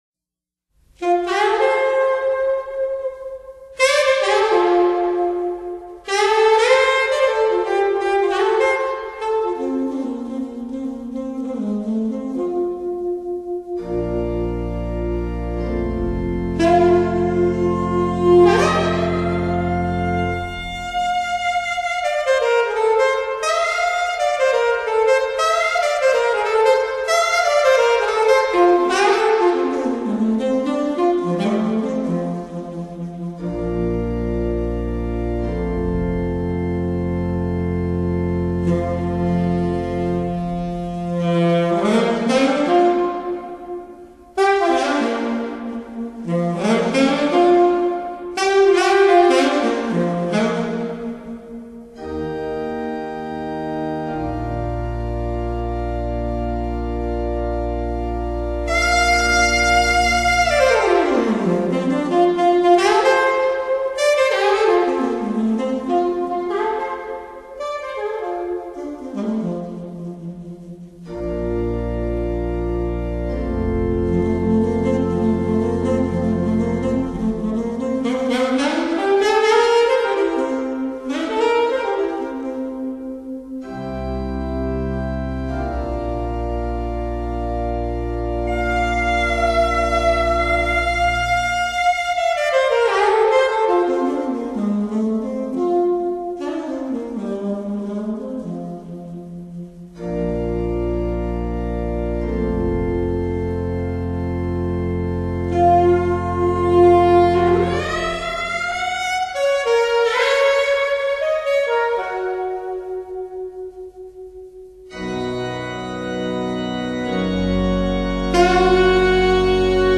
萨克斯
管风琴